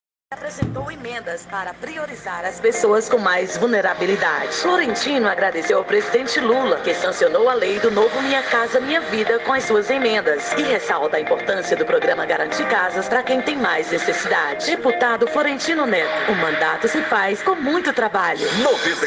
A rádio Liderança, de maior audiência em Parnaíba, tem praticamente todos os seus blocos comerciais ocupados por spots promocionais de Florentino.